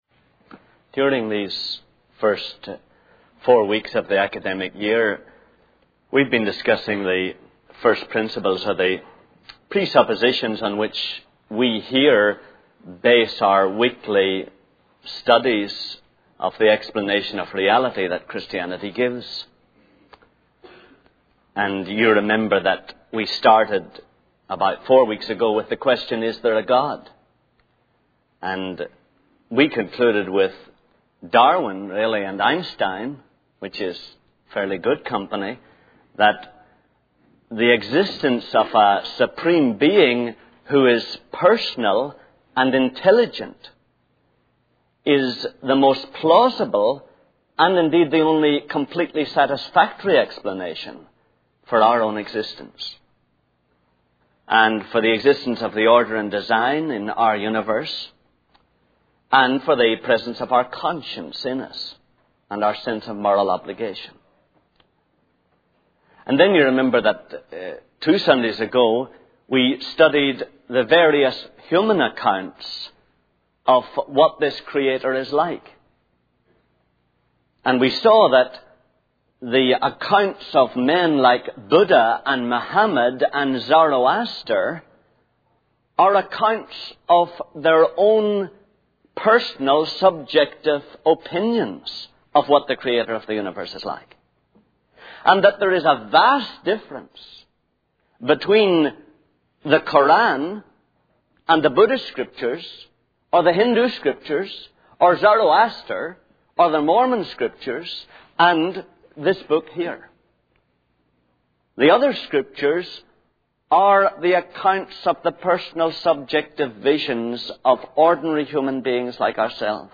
In this sermon, the speaker discusses the importance of aligning our will with God's will. He emphasizes the need to accept Jesus and surrender our old selfish lives to Him. The speaker highlights that believing in Jesus is not enough; it is our actions that truly matter.